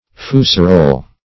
Search Result for " fusarole" : The Collaborative International Dictionary of English v.0.48: Fusarole \Fu"sa*role\, n. [F. fusarolle, fr. It. fusaruolo, fr. fuso spindle, shaft of a column.